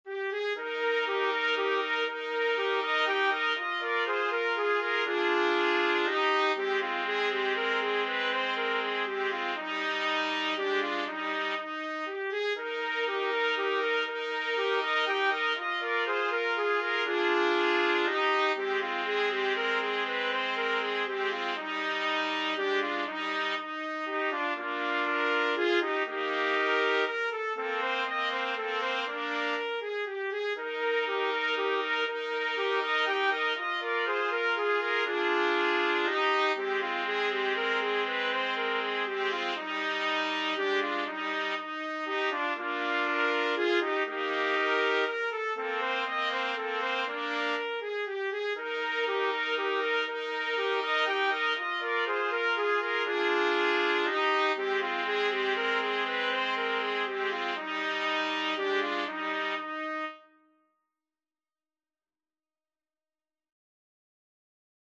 Trumpet 1Trumpet 2Trumpet 3
3/4 (View more 3/4 Music)
Classical (View more Classical Trumpet Trio Music)